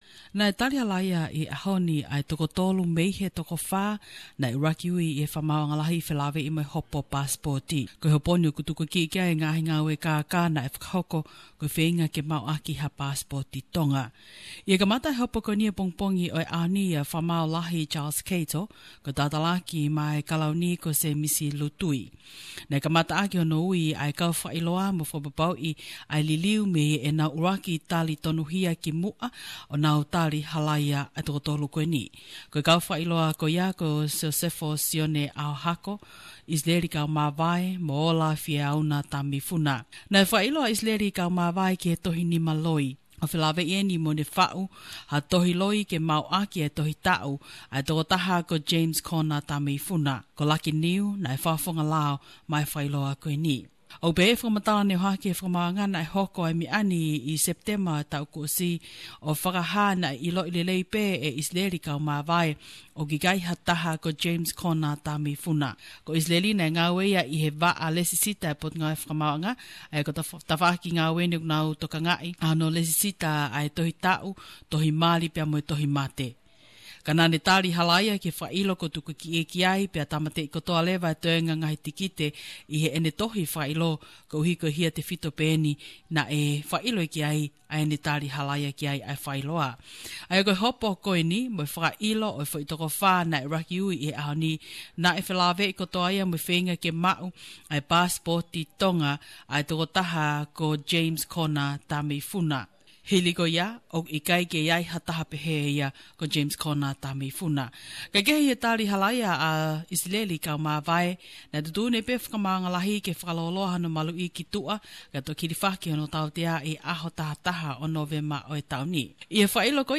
Tongan News from Tonga